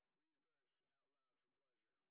sp04_exhibition_snr10.wav